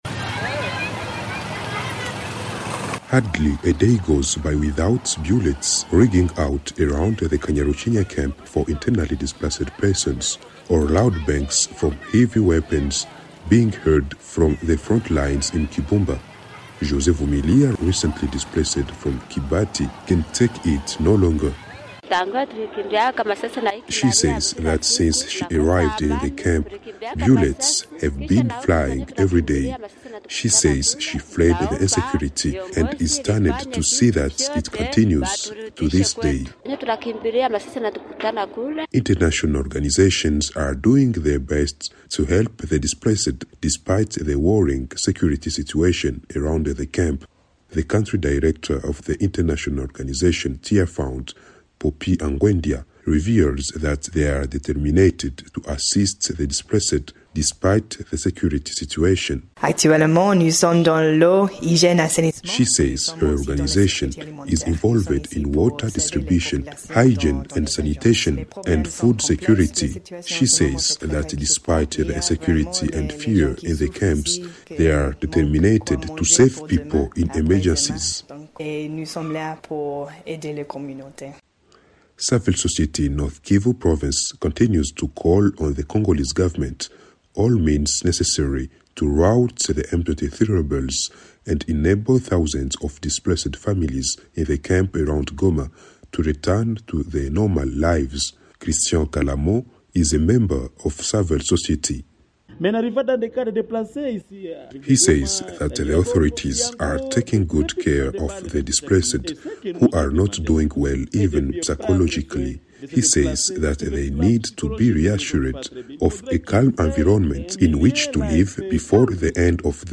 Breaking News